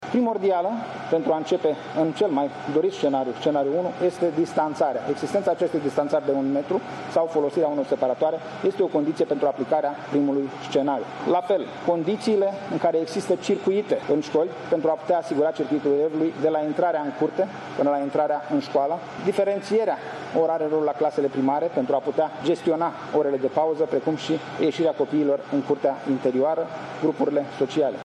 Pe de altă parte, ministrul Sănătății a explicat ce condiții trebuie să îndeplinească o școală pentru a primi toți elevii în clase, fizic, în același timp.